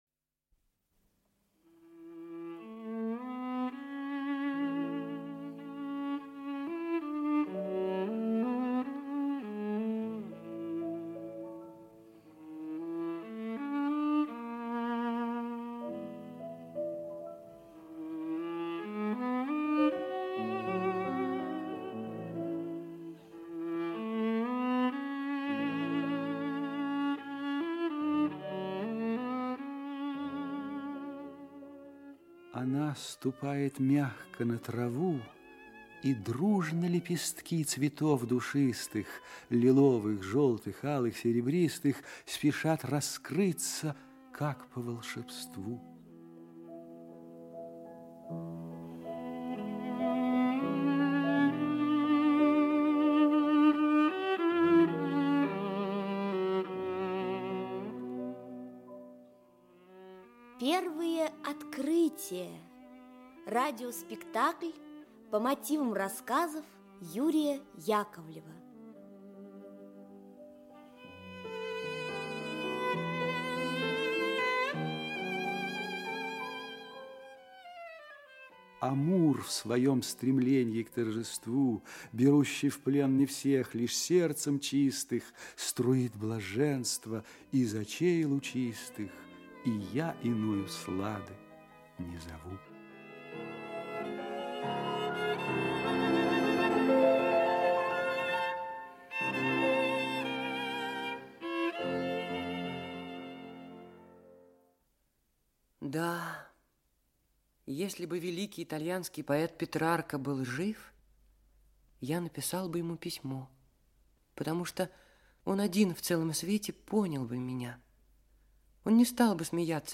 Аудиокнига Первое открытие. Часть 1 | Библиотека аудиокниг
Часть 1 Автор Юрий Яковлев Читает аудиокнигу Актерский коллектив.